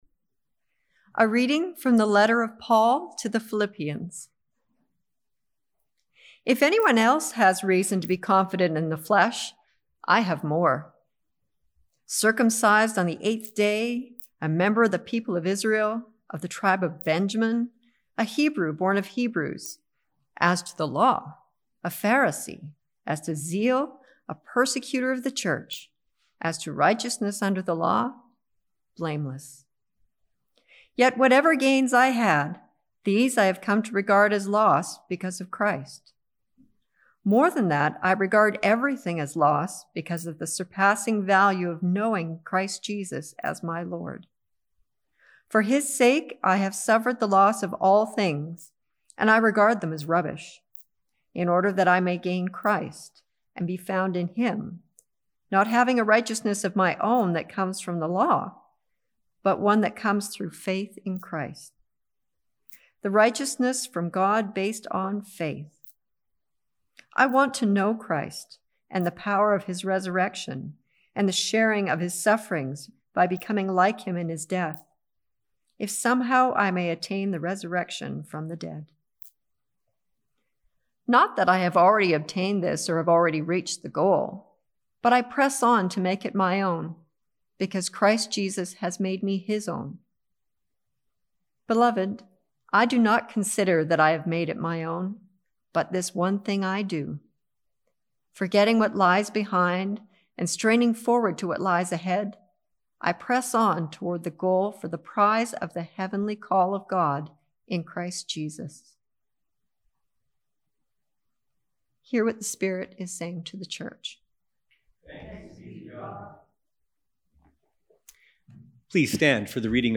Sermons | The Church of the Good Shepherd